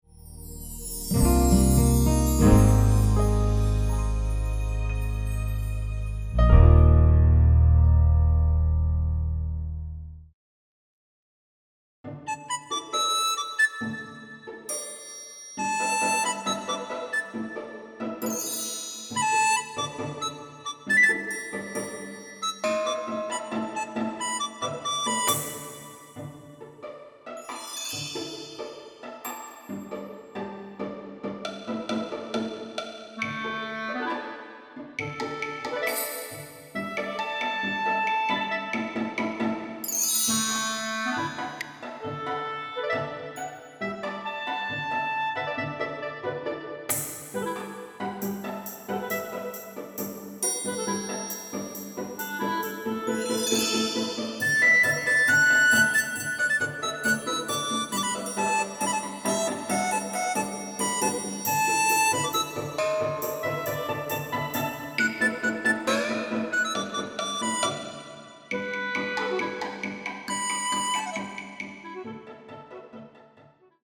标签 国风 暗黑 民乐 励志 热血
以琵琶与笛子演绎活泼灵动的民族节奏